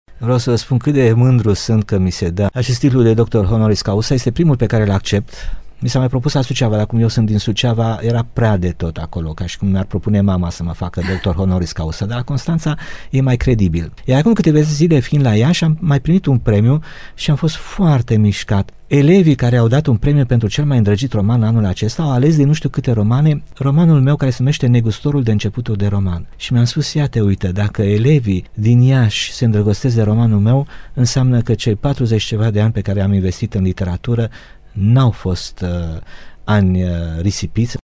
Senatul Universităţii Ovidius i-a oferit astăzi titlul de Doctor Honoris Causa lui Matei Vişniec, personalitate marcantă a culturii mondiale. Vizibil emoţionaţ dramaturgul a ţinut să mulţumească tinerilor din Constanţa pentru alegerea făcută: Interviul integral cu marele dramaturg poate fi ascultat astăzi